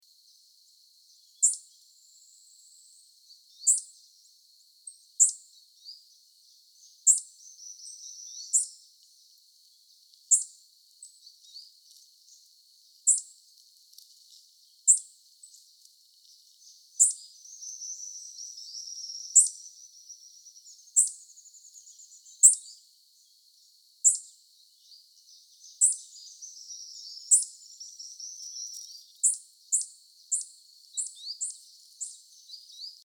Tangará Alisero (Thlypopsis ruficeps)
Microfono Sennheiser ME66 + K6 (Rycote Grip+Windscreen)
Grabadora Marantz PDM 661
Nombre en inglés: Rust-and-yellow Tanager
Localidad o área protegida: Parque Provincial Potrero de Yala
Condición: Silvestre
Certeza: Observada, Vocalización Grabada